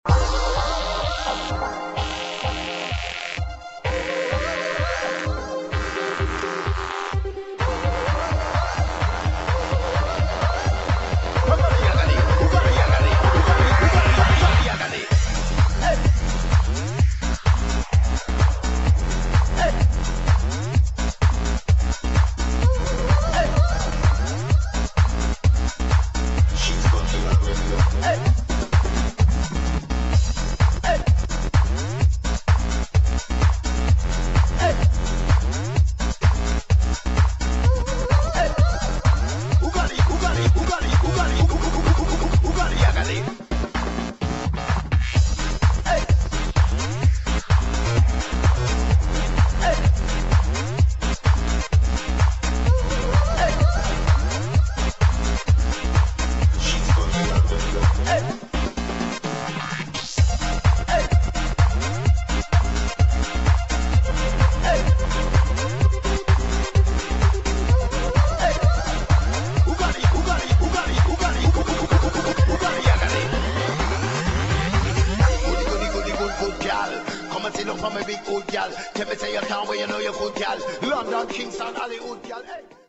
[ BREAKS | HOUSE ]